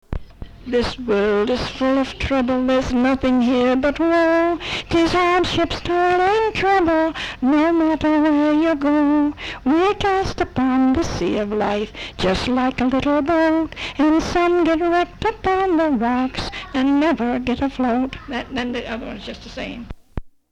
Folk songs, English--Vermont
sound tape reel (analog)